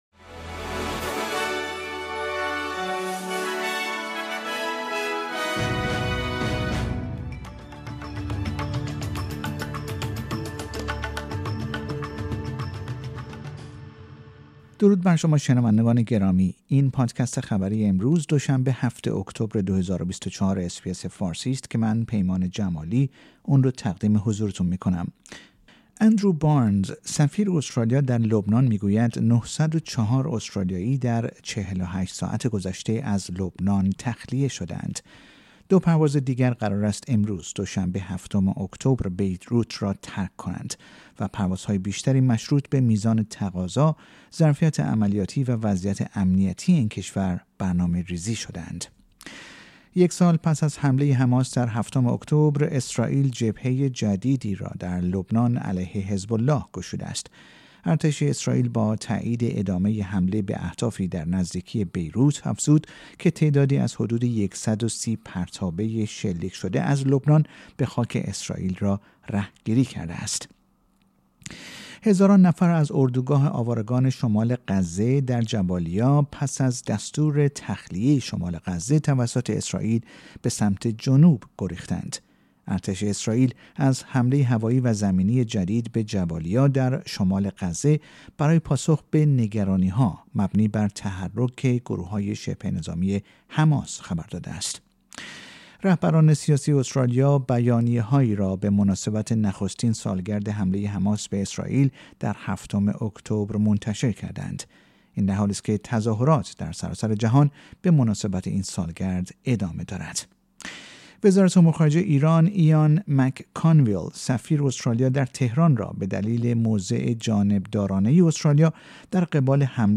در این پادکست خبری مهمترین اخبار استرالیا در روز دوشنبه ۷ اکتبر ۲۰۲۴ ارائه شده است.